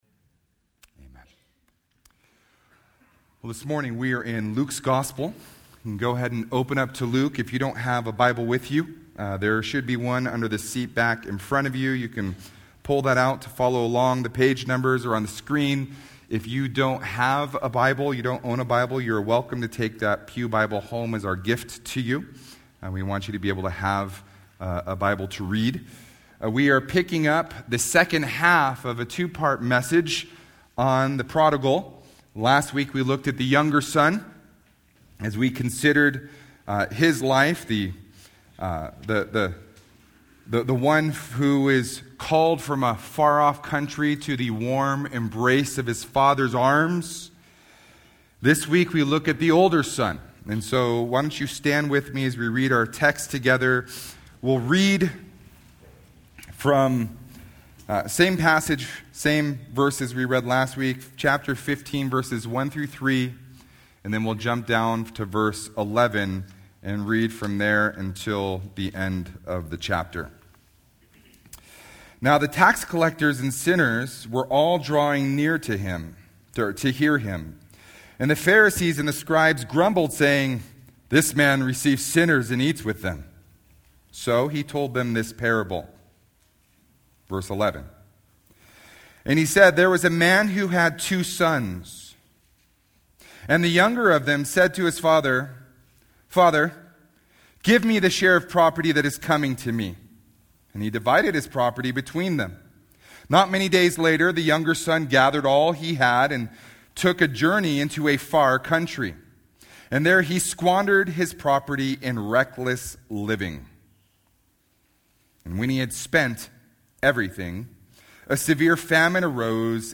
A message from the series "General."